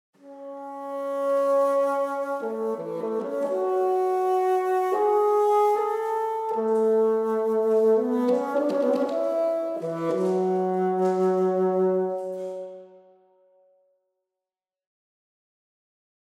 Deutlich umfangreicher ist das Angebot an Effekten bei den Flöten:
Overtone Sforzandi: